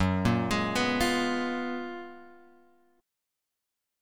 F# Major 11th